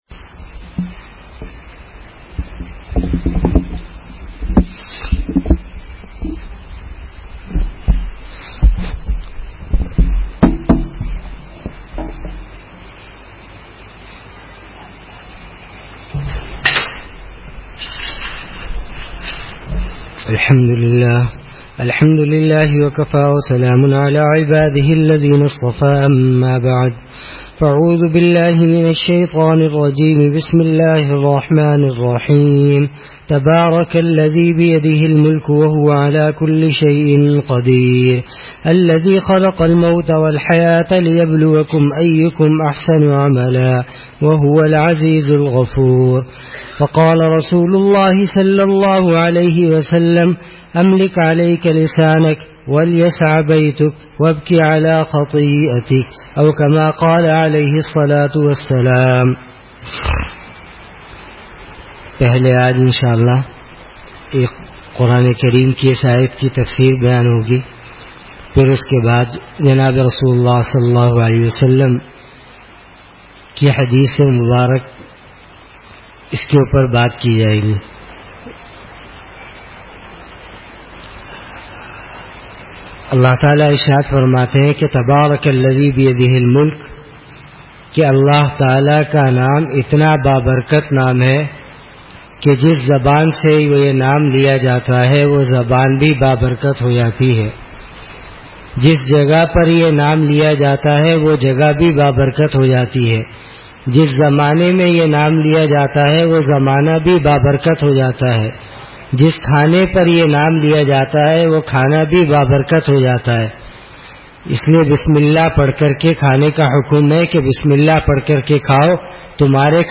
Bayan : 2014-12-05 . . . . . Adaab-ul-Ma’ashrat |